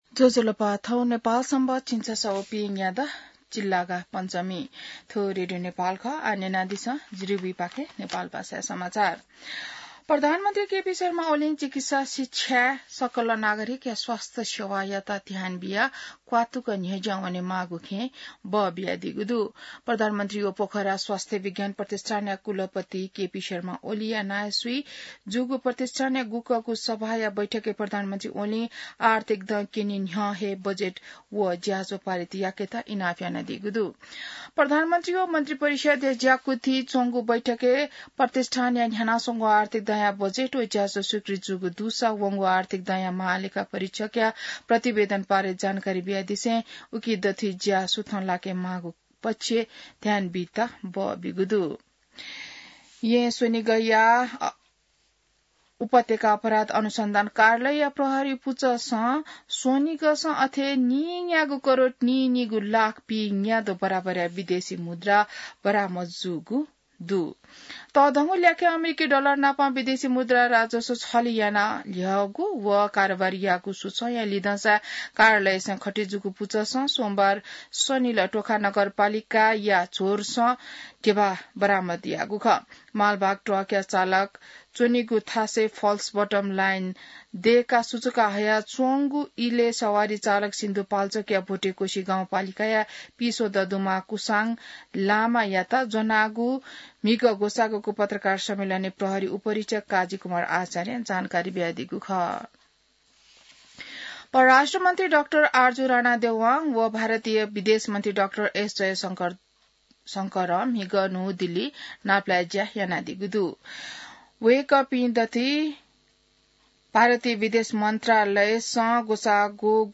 नेपाल भाषामा समाचार : ६ चैत , २०८१